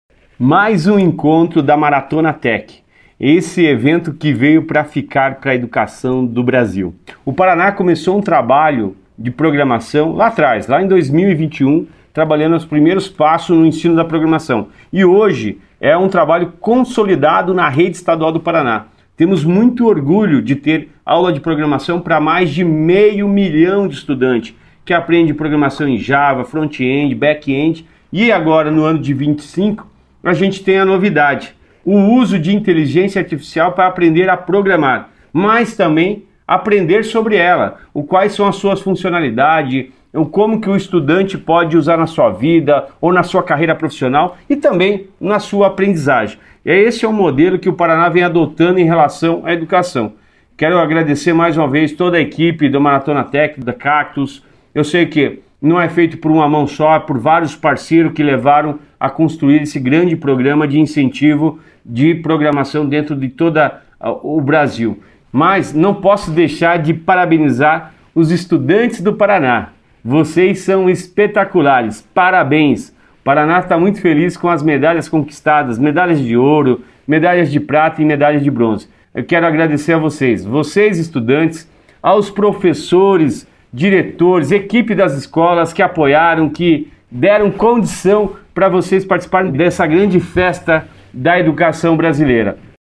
Sonora do secretário da Educação, Roni Miranda, sobre o tricampeonato paranaense conquistado na Maratona Tech